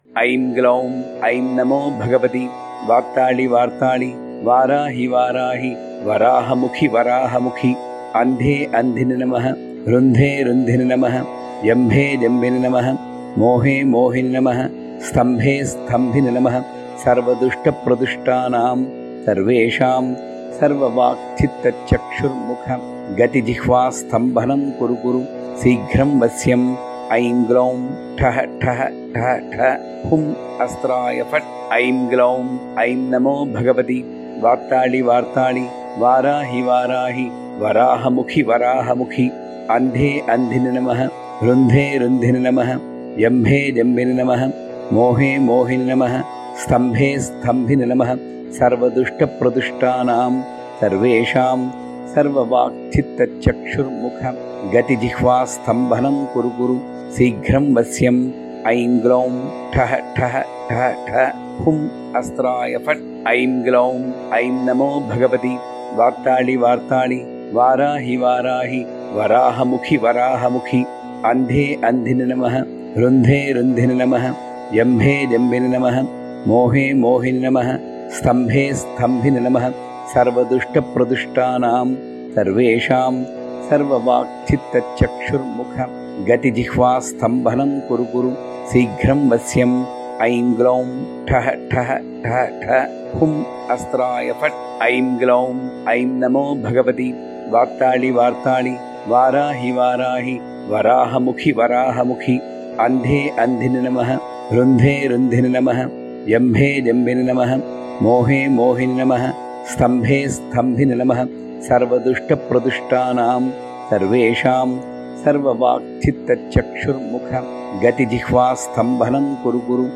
Varahi_mantram_Tanpura-108.mp3